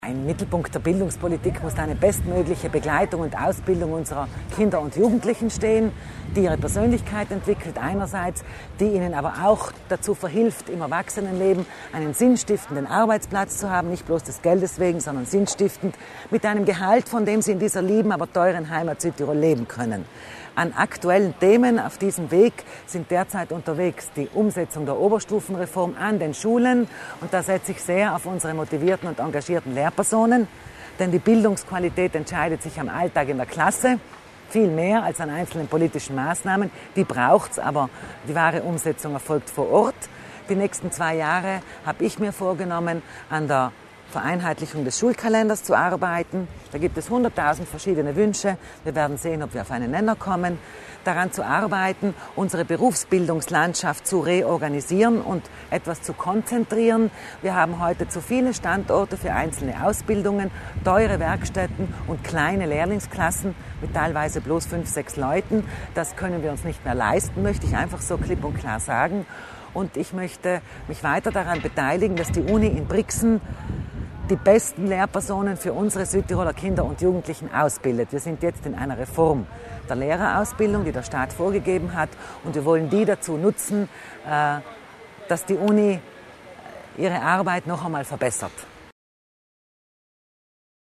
Landesrätin Sabina Kaslatter Mur über die Zukunft der deutschen Schule